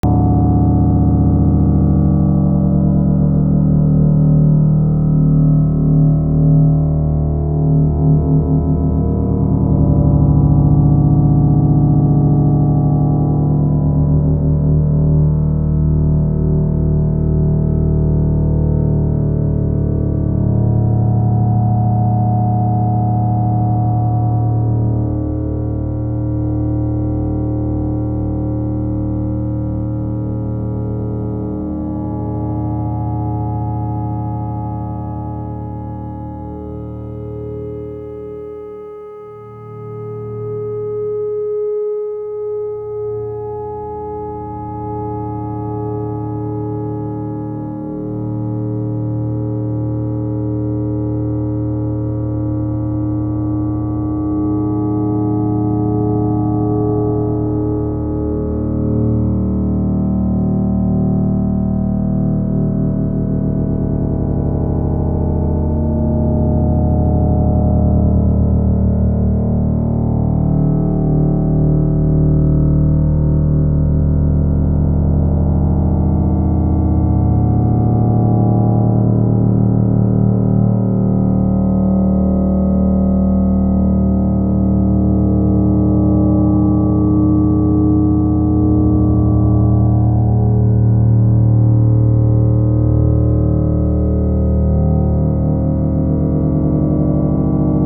More ring mod.
Modular
Microfreak